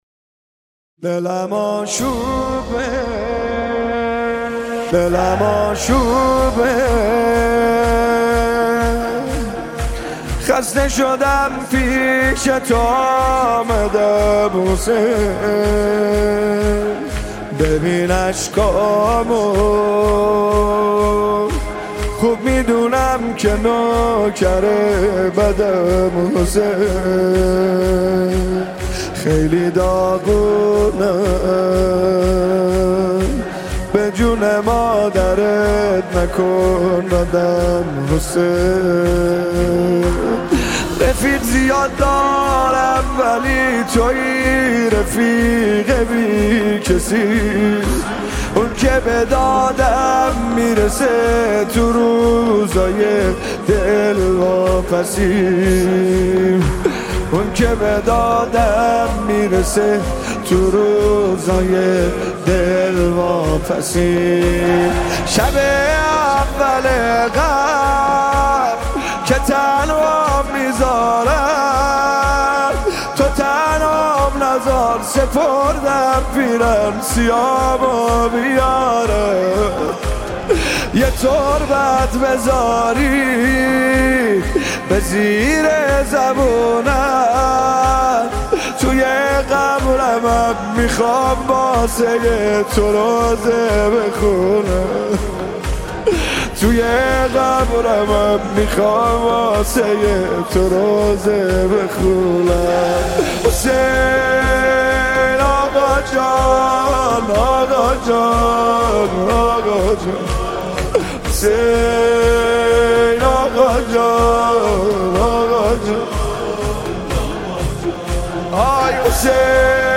نماهنگ و مناجات دلنشین